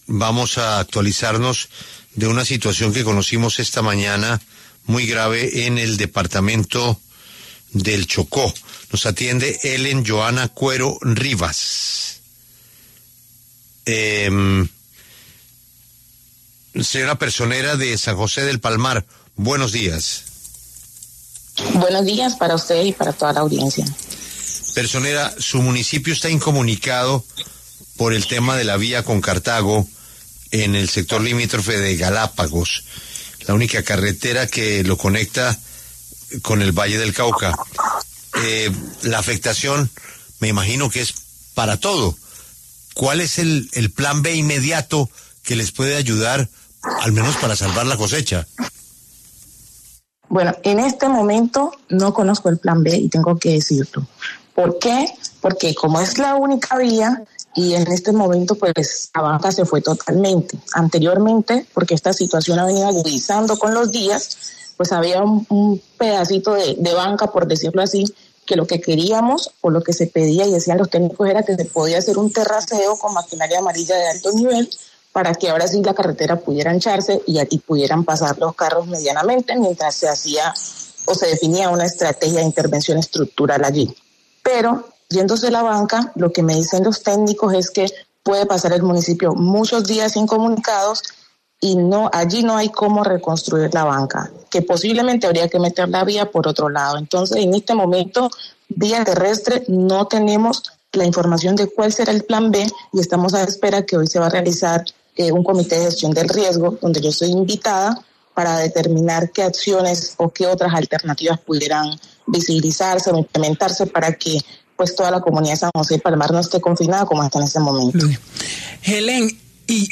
En diálogo con La W, la personera Ellen Johana Cuero se refirió a la pérdida total de la vía que conecta con Cartago.